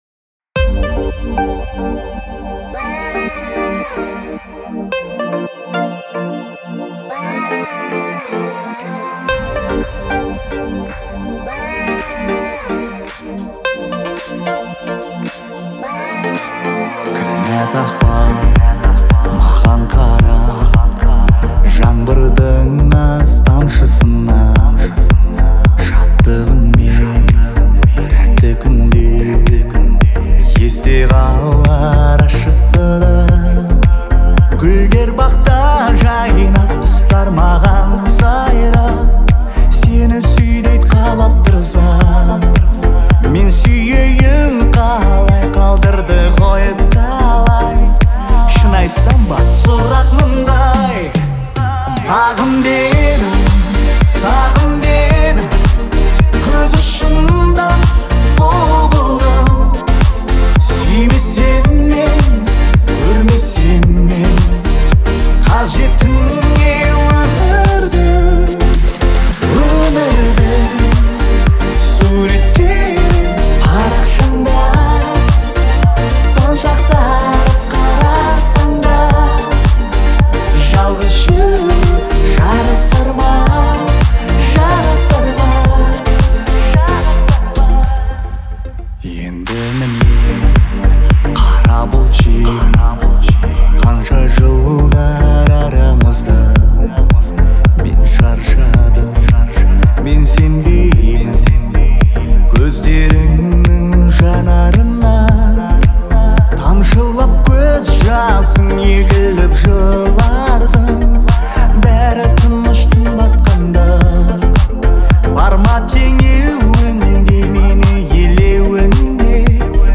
это душевный трек в жанре казахского поп